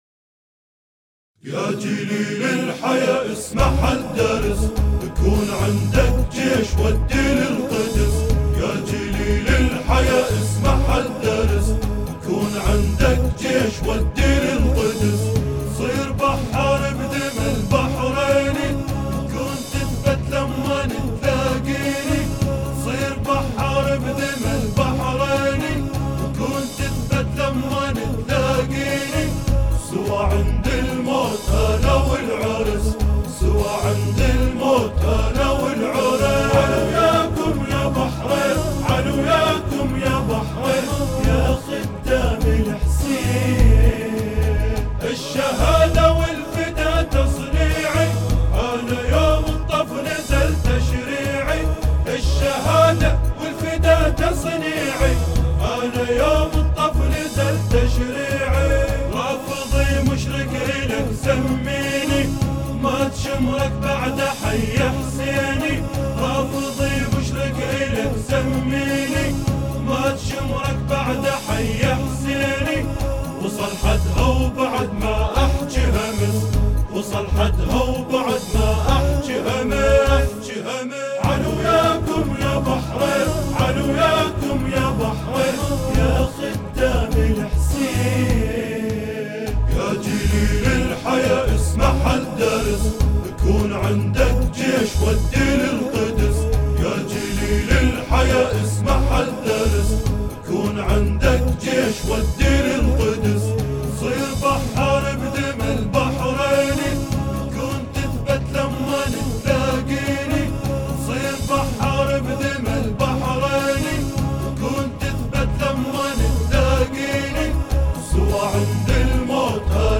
أناشيد بحرينية
اناشيد وطنية